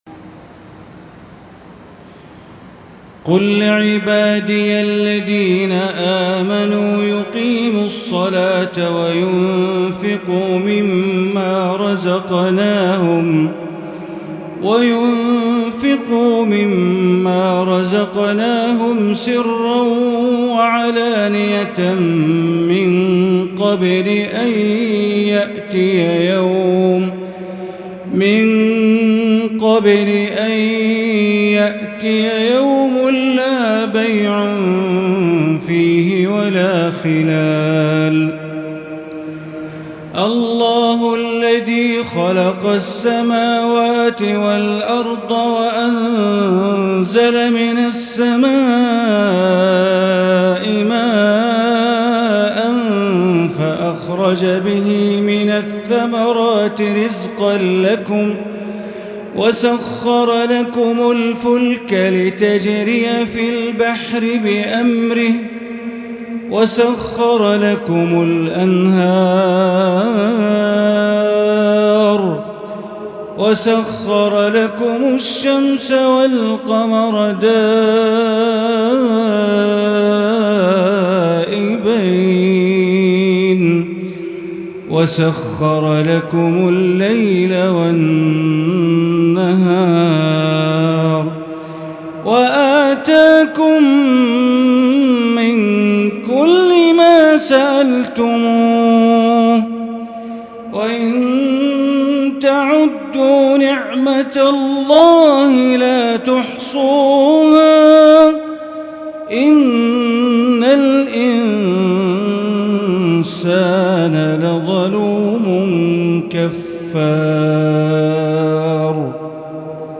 مغرب ٣-١-١٤٤٢ هـ تلاوة من سورتي إبراهيم والإسراء > 1442 هـ > الفروض - تلاوات بندر بليلة